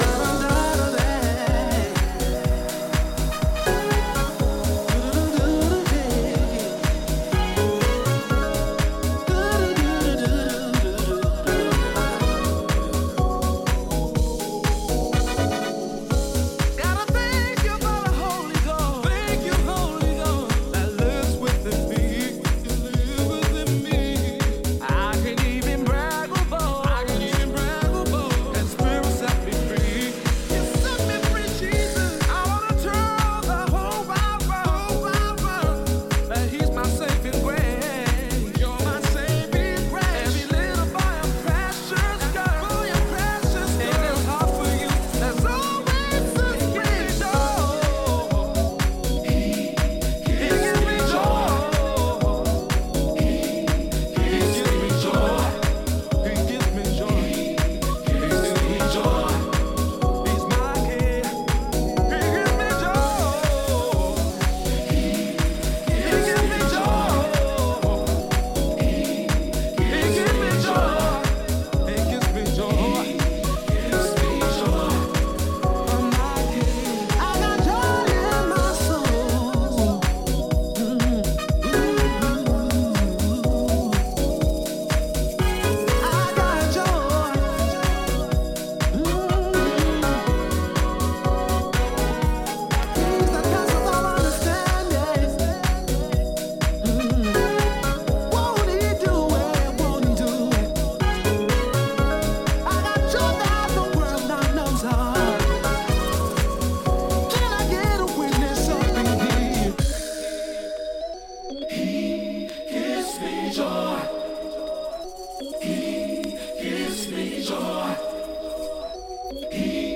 Italian deep house
South African singer
lays down warm keys, rolling percussion and fluid basslines